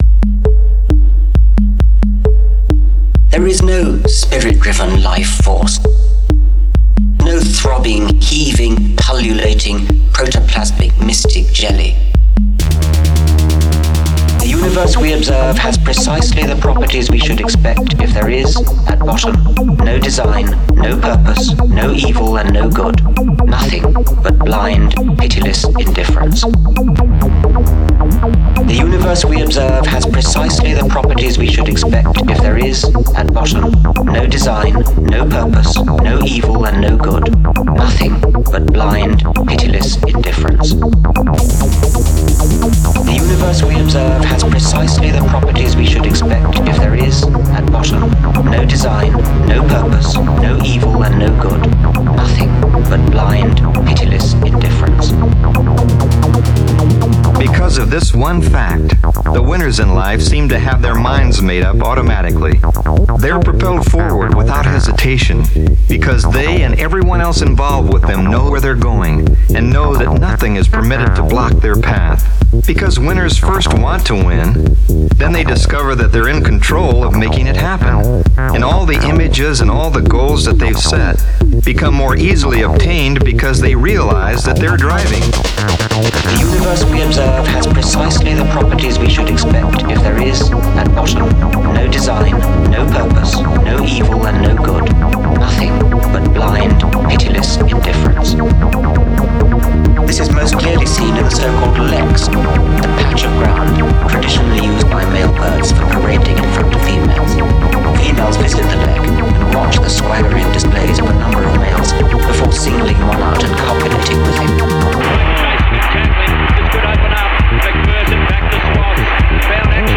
Get your nihilistic acid techno before it's too late...